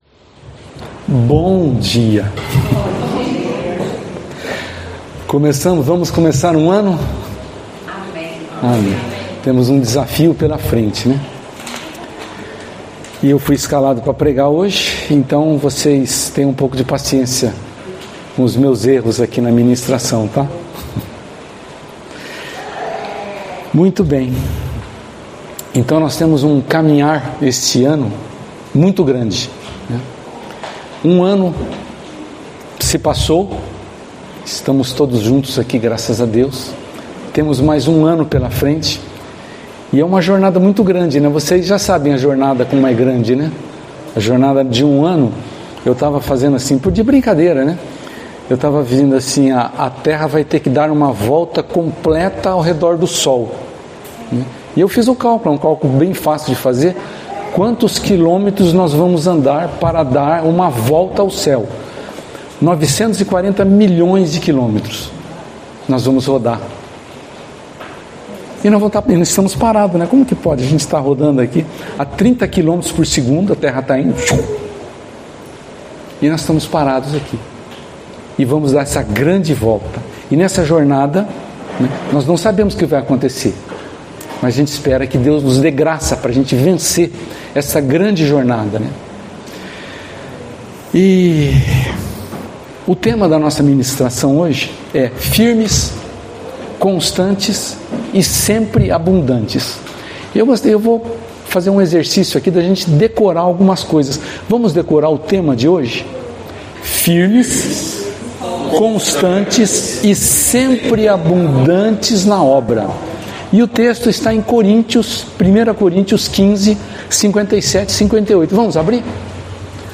no culto do dia 18/01/2026 – Tema: Firmes, constantes e sempre abundantes na obra do Senhor
Palavras ministradas